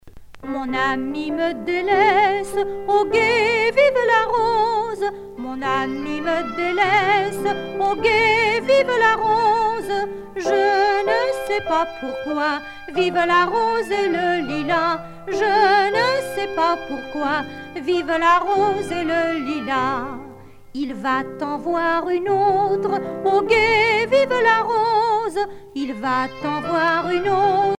Ronde lorraine
danse : rondes enfantines (autres)
Pièce musicale éditée